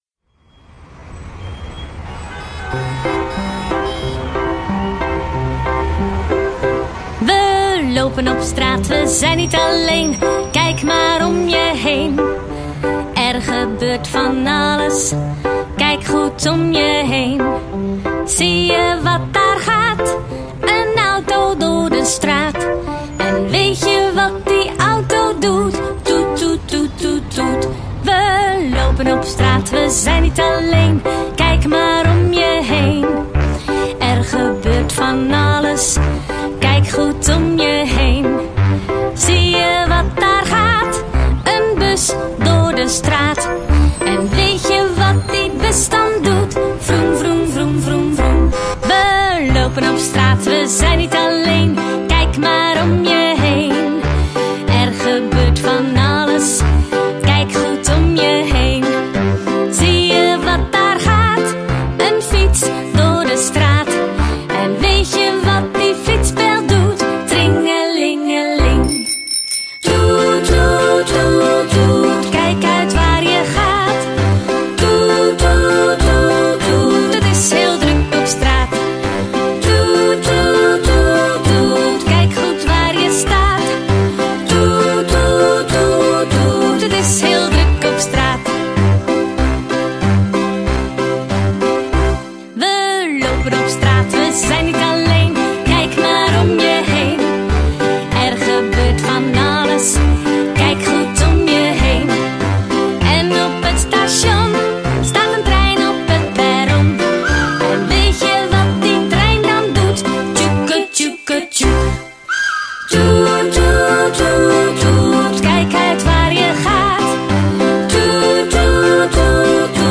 lied
(staat in C)